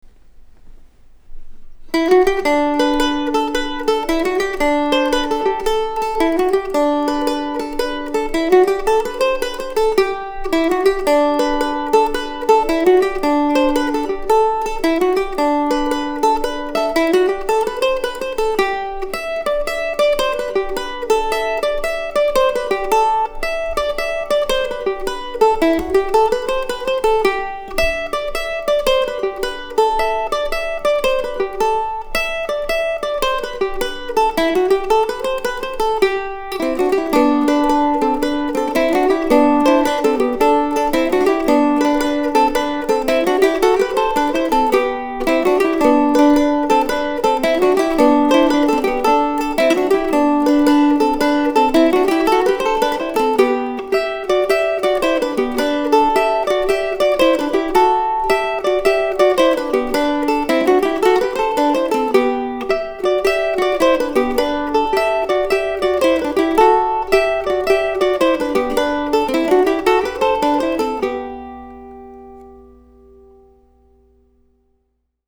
(The harmony part didn't appear in the dream but was composed later.)